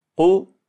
= tiden som behövs för att uttala قُ (Qu)